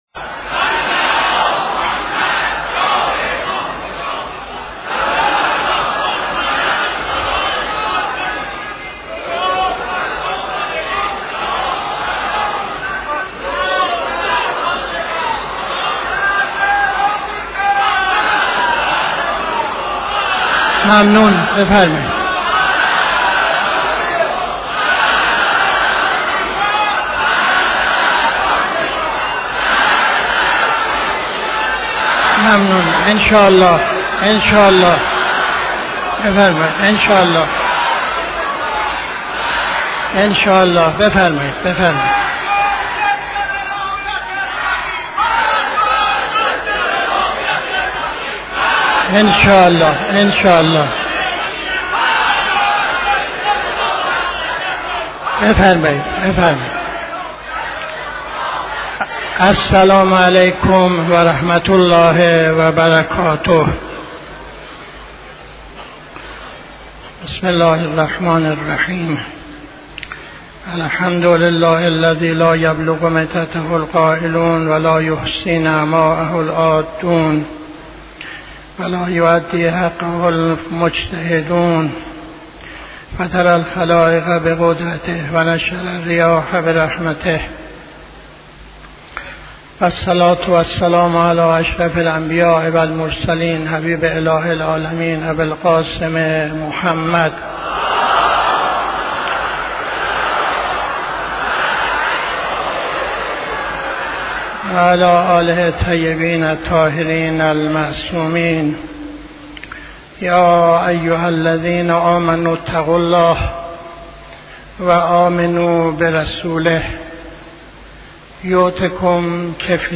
خطبه اول نماز جمعه 18-11-81